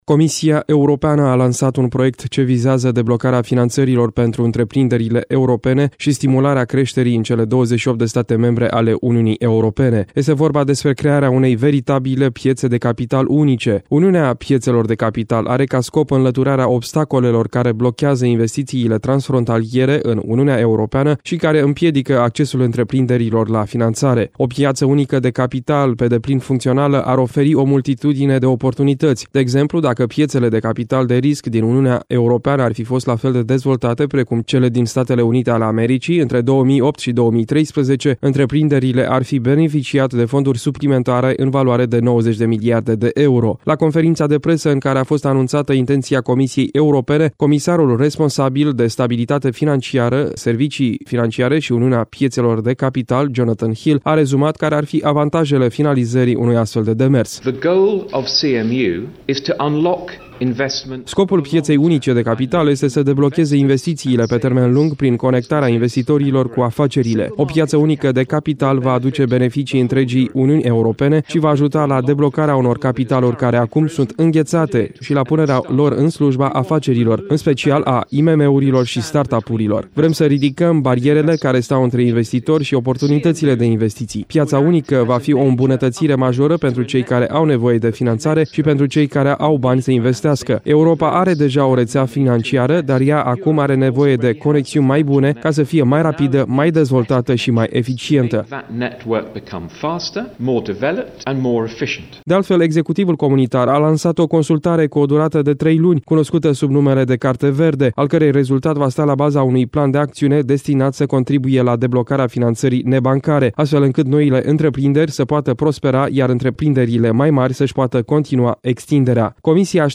La conferința de presă în care a fost anunțată intenția Comisiei Europene, comisarul responsabil de stabilitatea financiara, serviciile financiare şi uniunea pieţelor de capital, Jonathan Hill a rezumat care ar fi avantajele finalizării unui astfel de demers.